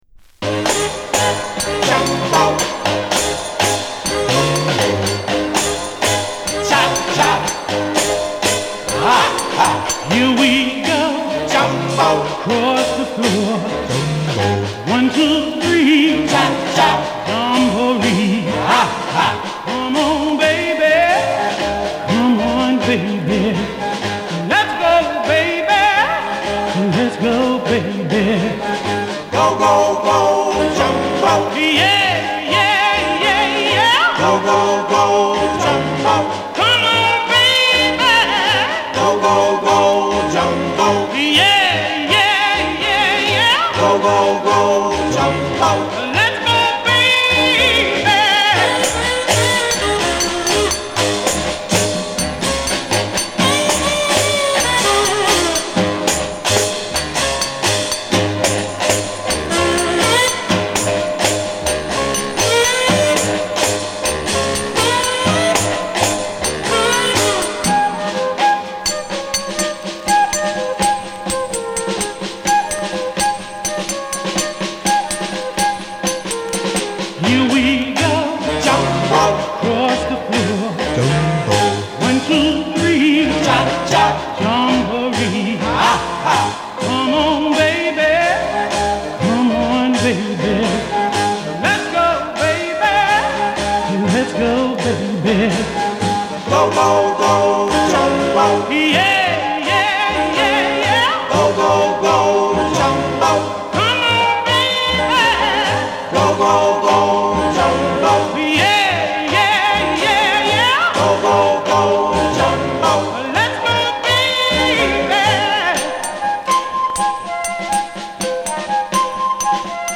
B面はプリミティヴなリズムに引き込まれるジャングルR&Bロッカー。
[Comped] [Tittyshaker] [Exotica] [NEW]
バックグラウンドノイズはいります。